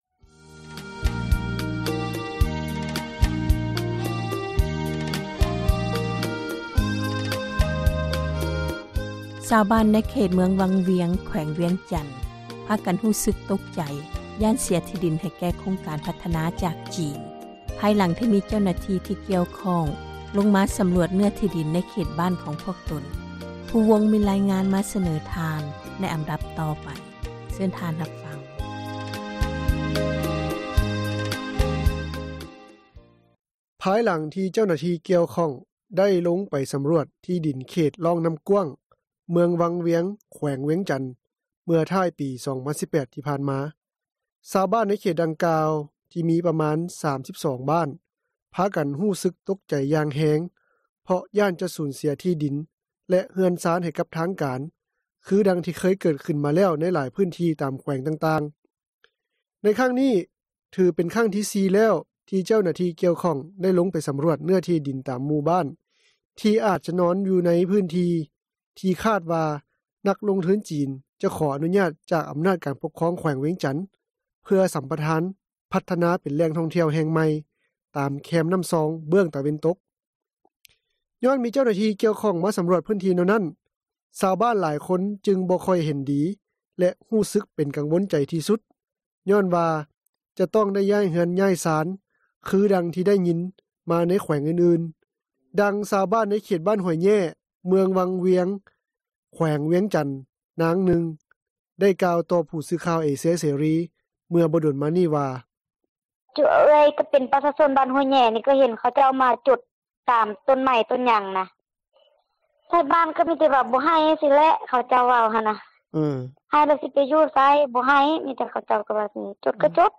ດັ່ງຊາວບ້ານໃນເຂດບ້ານຫ້ວຍແຍ້, ເມືອງວັງວຽງ ແຂວງວຽງຈັນ ນາງນຶ່ງໄດ້ກ່າວ ຕໍ່ຜູ້ສື່ຂ່າວເອເຊັຽເສຣີ ເມື່ອບໍ່ດົນມານີ້ວ່າ: